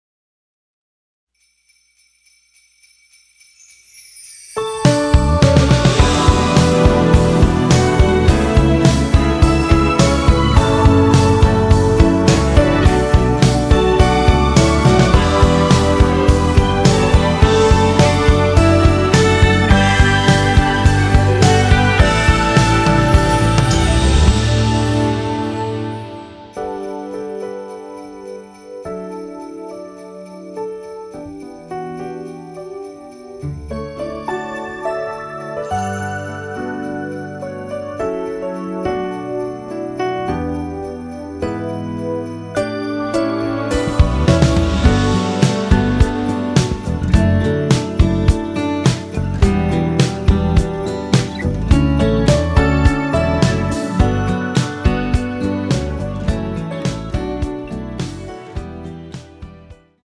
D
앞부분30초, 뒷부분30초씩 편집해서 올려 드리고 있습니다.
중간에 음이 끈어지고 다시 나오는 이유는
공식 MR